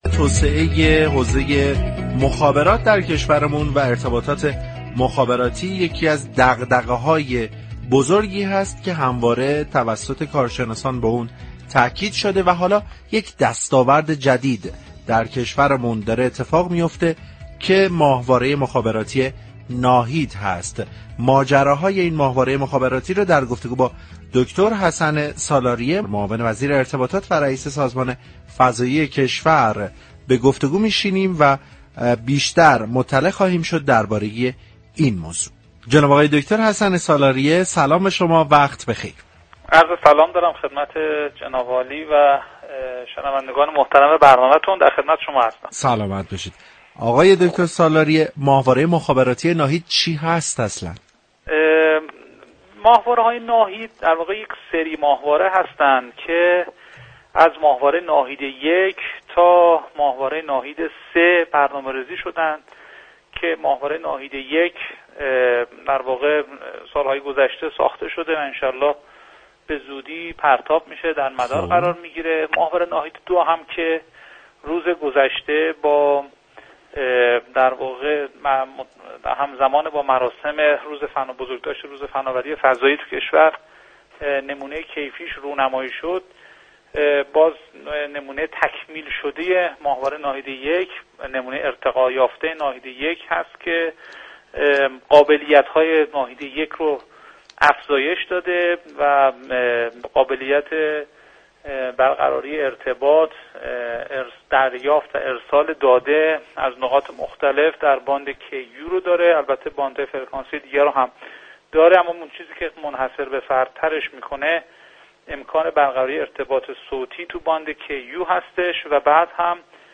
به گزارش پایگاه اطلاع رسانی رادیو تهران، حسن سالاریه معاون وزیر ارتباطات و فناوری اطلاعات و رئیس سازمان فضایی كشور در گفت و گو با «علم بهتر است» در خصوص ماهواره‌‌های مخابراتی «ناهید» اظهار داشت: ماهواره‌های ناهید 1 تا 3 از سری ماهواره‌های مخابراتی هستند كه ساخت آنها از سالها پیش آغاز شده است.